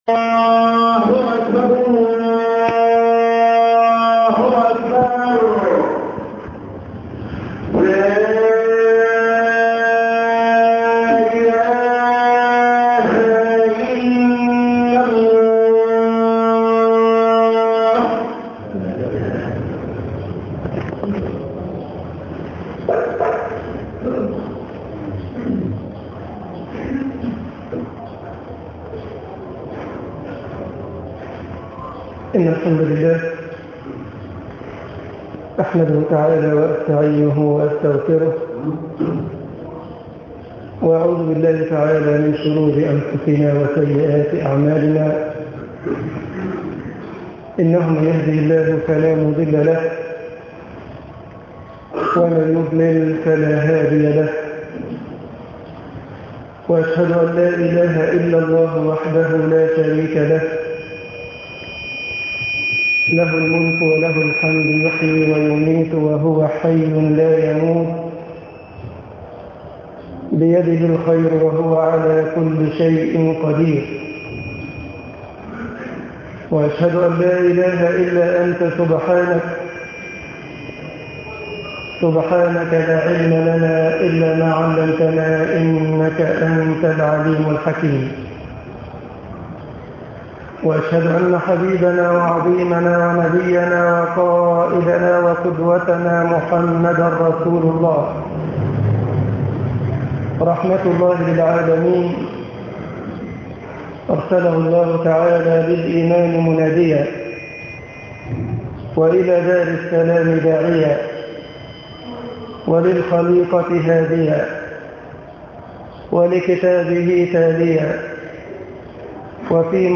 غزاة الاعراض بالامراض طباعة البريد الإلكتروني التفاصيل كتب بواسطة: admin المجموعة: مواضيع مختلفة Download مسجد التوحيد طنطا كتامة التفاصيل نشر بتاريخ: الثلاثاء، 13 نيسان/أبريل 2010 23:43 الزيارات: 3872 السابق التالي